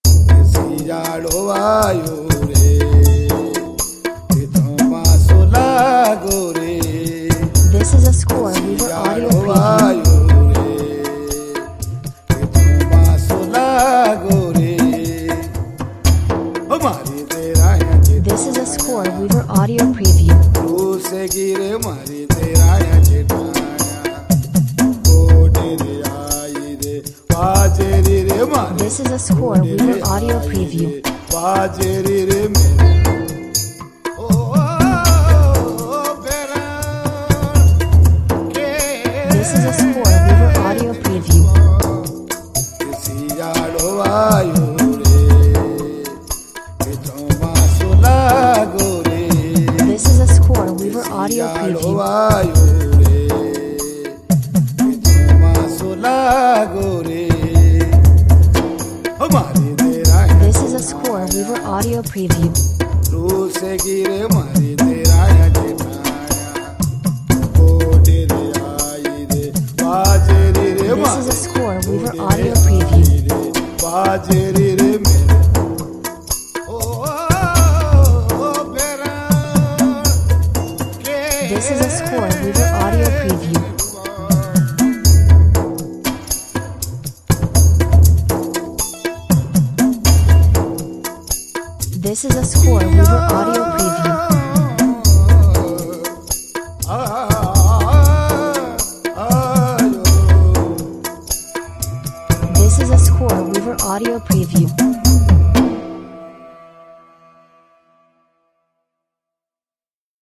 Hypnotic Indian/Pakistani music with fantastic chants.